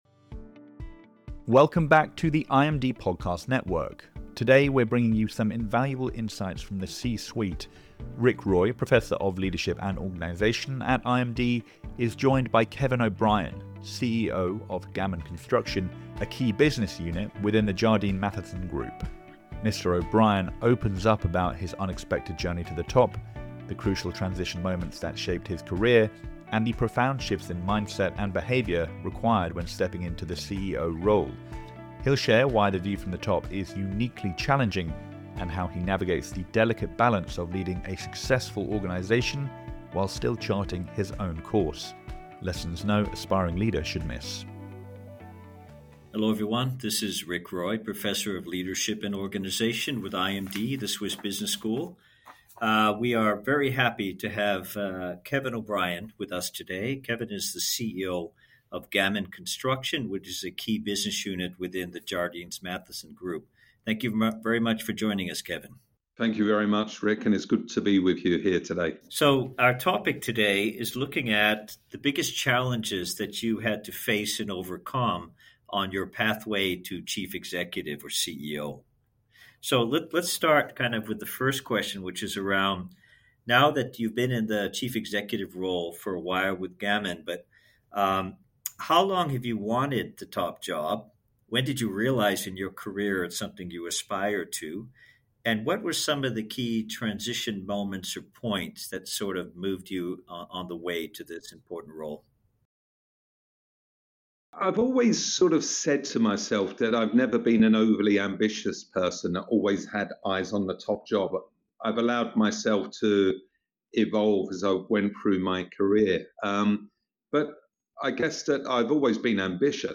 CEO interview